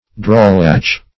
Drawlatch \Draw"latch`\, n. A housebreaker or thief.